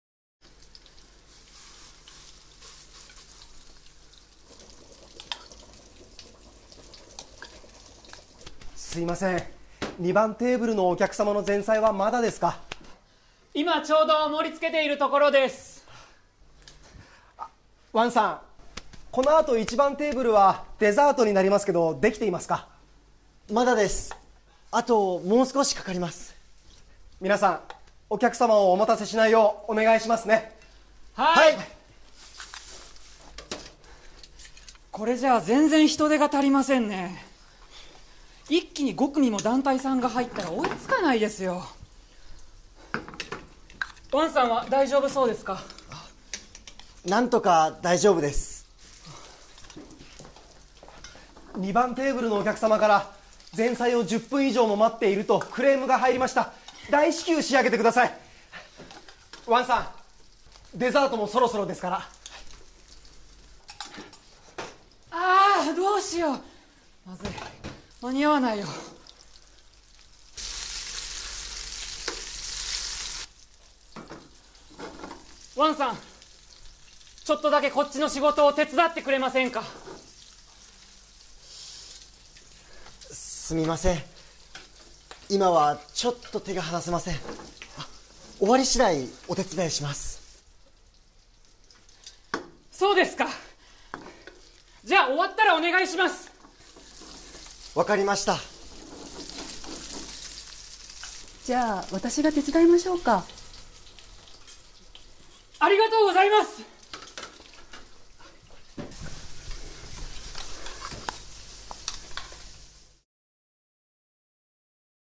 Role-play Setup